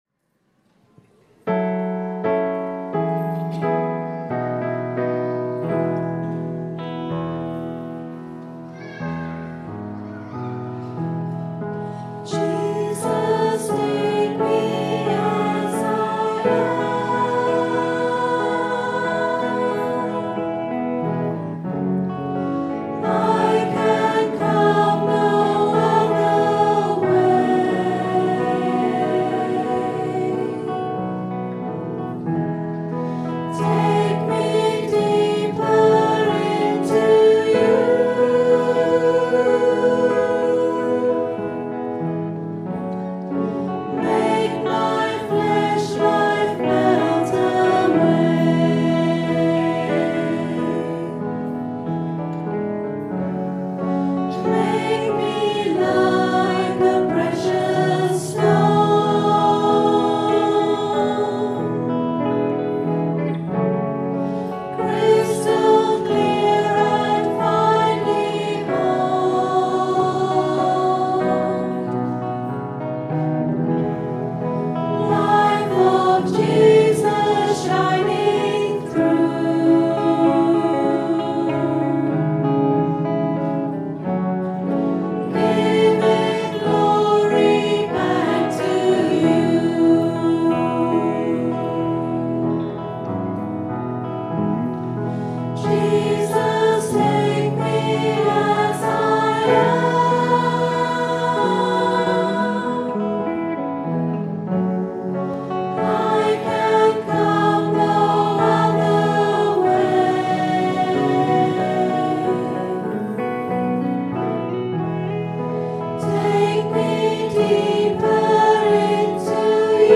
Recorded on a Zoom H4 digital stereo recorder at 10am Mass, 4th September 2011.